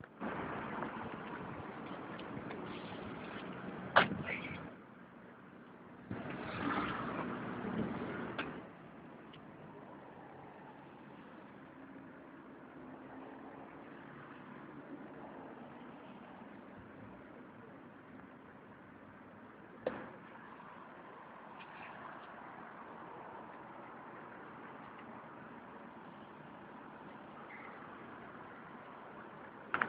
Bruits de moteurs, cloche